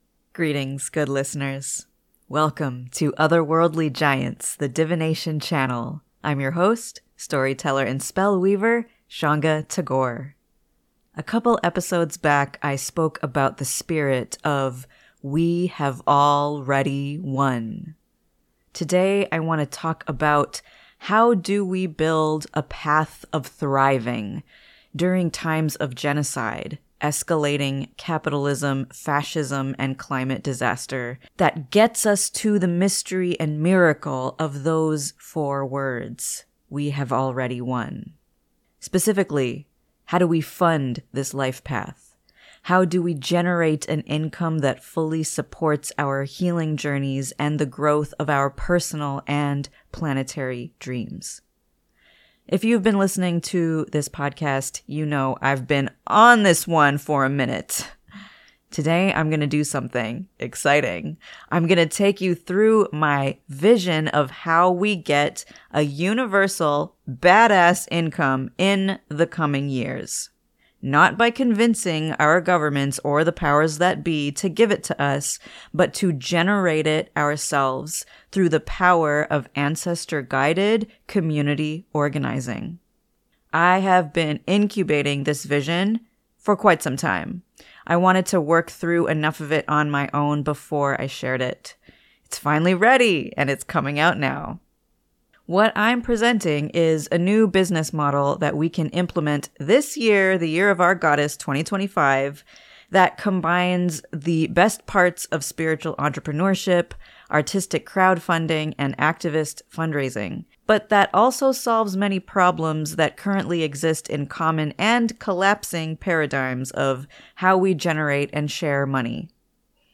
Curl up with this podcast episode sound bath